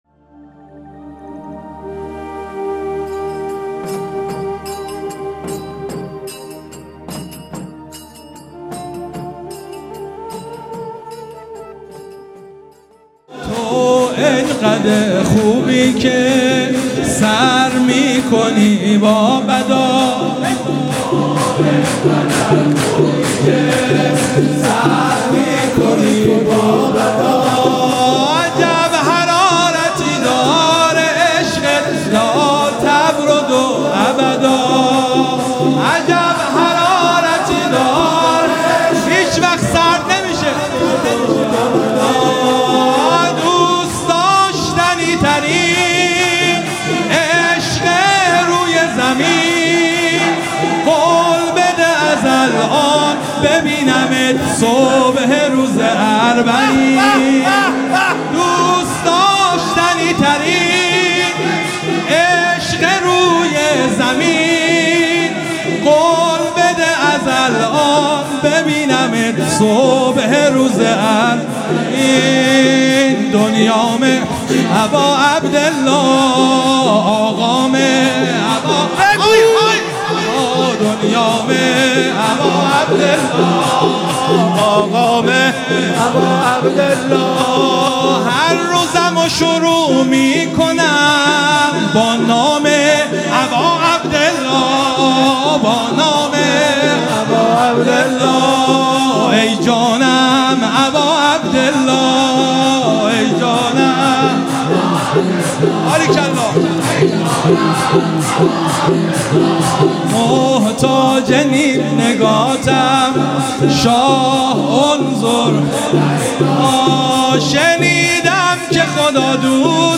مداحی شور
دهه اول - شب سوم محرم 1402